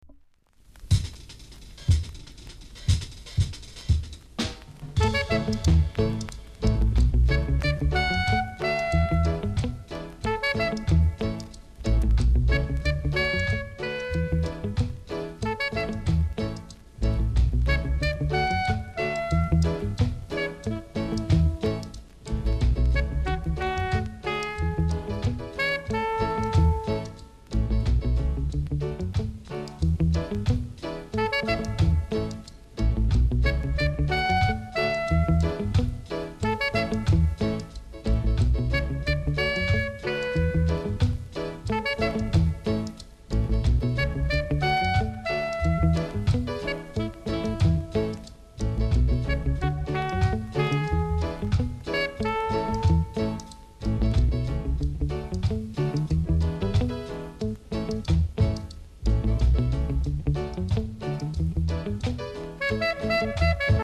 ※小さなチリノイズが少しあります。
コメント CALYPSO!!